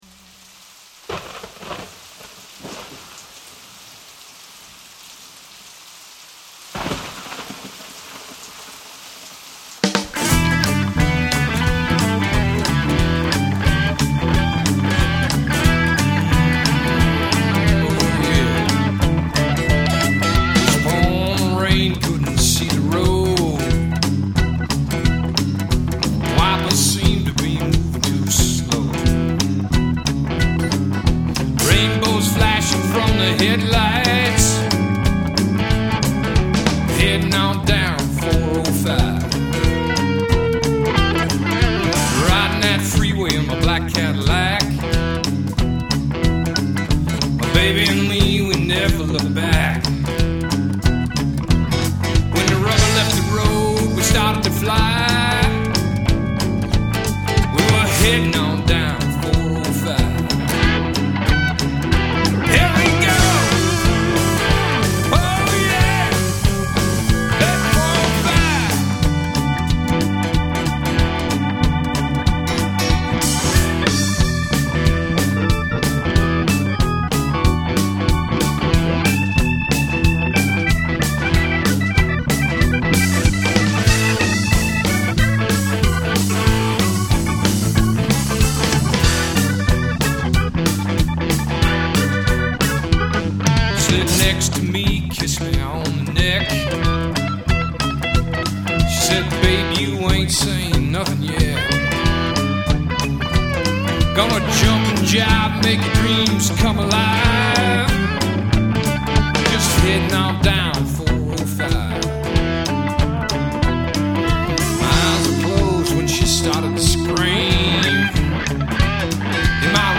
RECORDED at Arcade Digital Studios, Tumwater, WA
Guitars
Keyboards
Drums